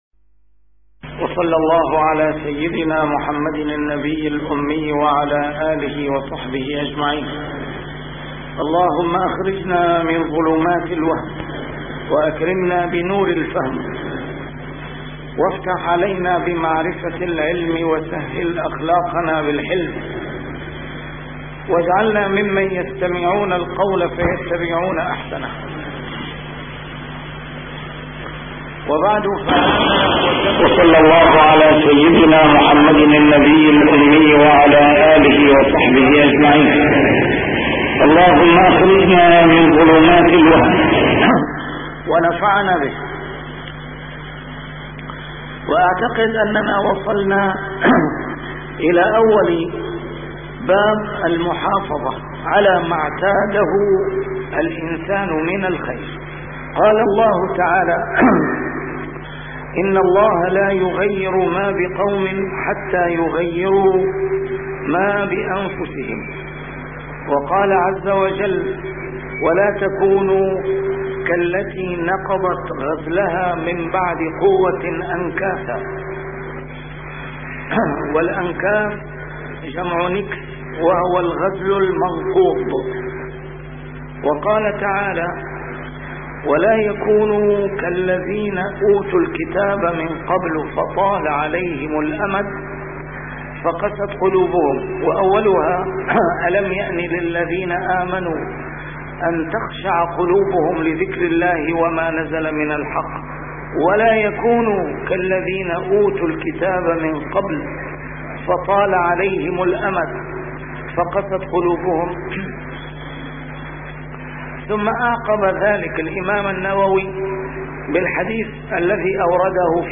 A MARTYR SCHOLAR: IMAM MUHAMMAD SAEED RAMADAN AL-BOUTI - الدروس العلمية - شرح كتاب رياض الصالحين - 614- شرح رياض الصالحين: المحافظة على ما اعتاده من الخير